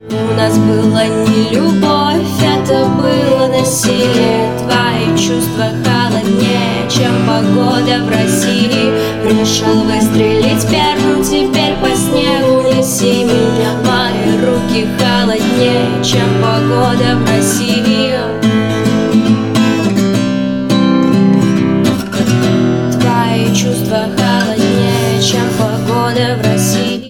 поп
гитара
акустика